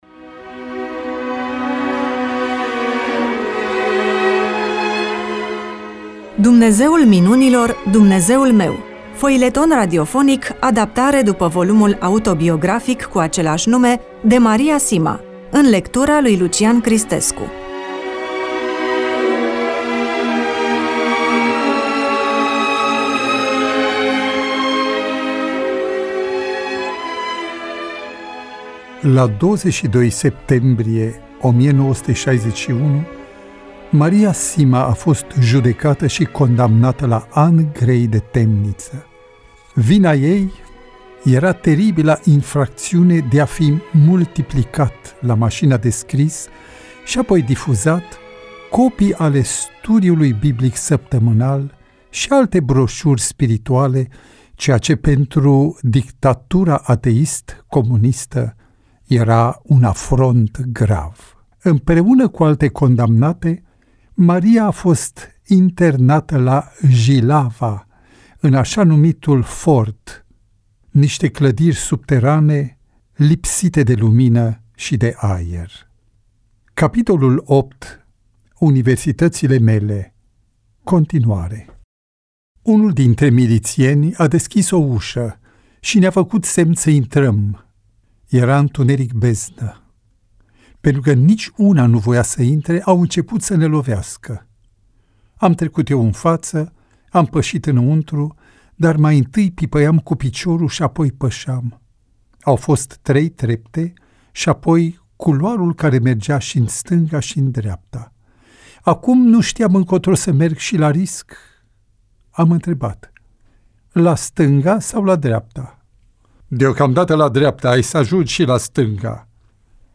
EMISIUNEA: Roman foileton DATA INREGISTRARII: 05.12.2025 VIZUALIZARI: 25